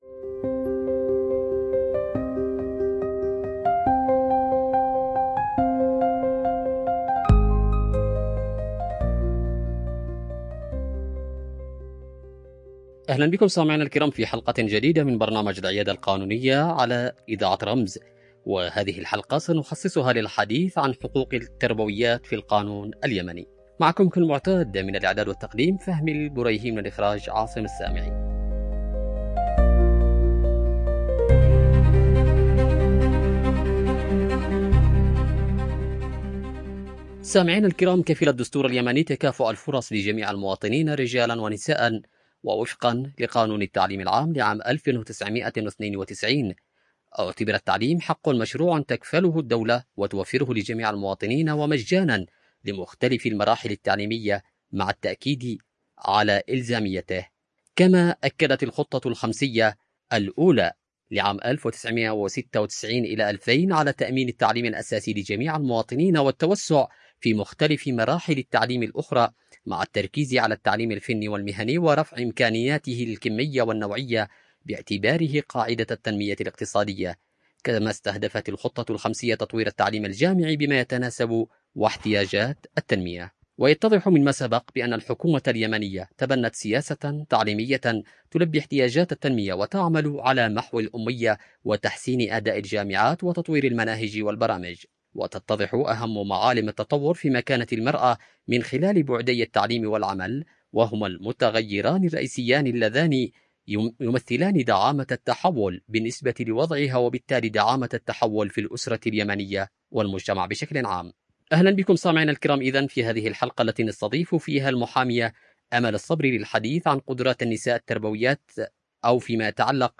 📻 عبر إذاعة رمز